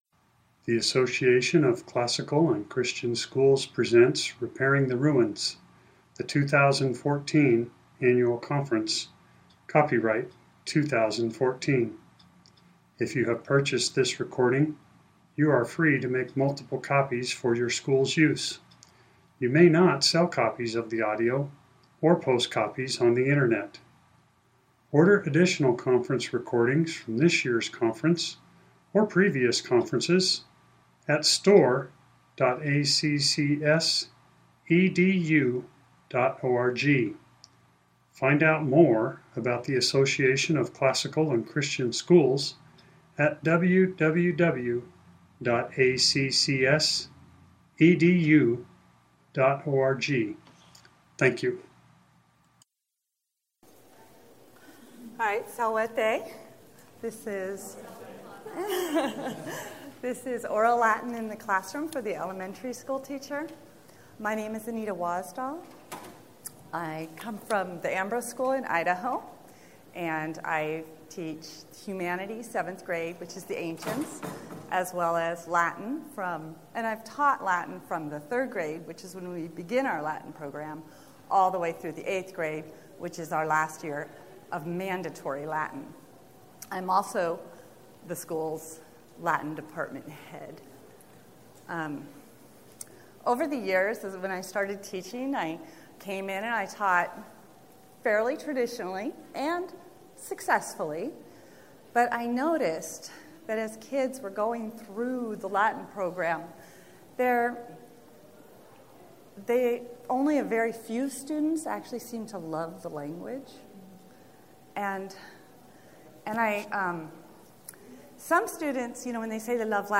2014 Workshop Talk | 0:57:39 | All Grade Levels, Latin, Greek & Language